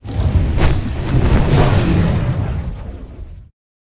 CosmicRageSounds / ogg / general / combat / creatures / dragon / he / prepare1.ogg